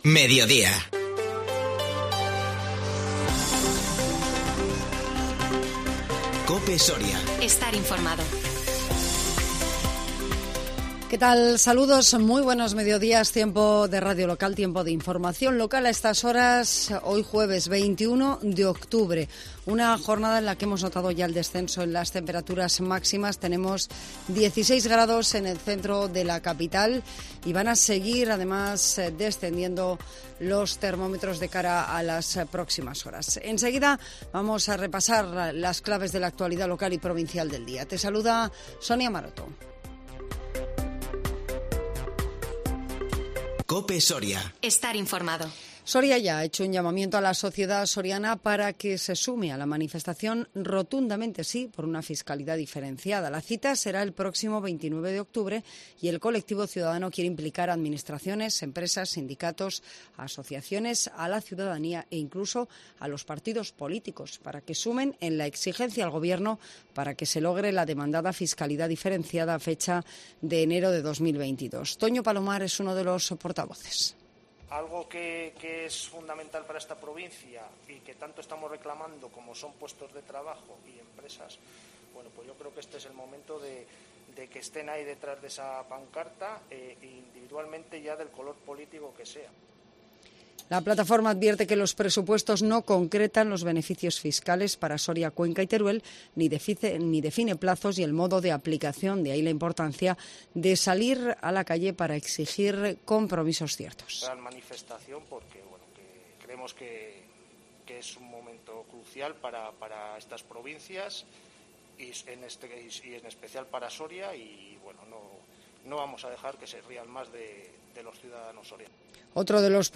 INFORMATIVO MEDIODÍA 21 OCTUBRE 2021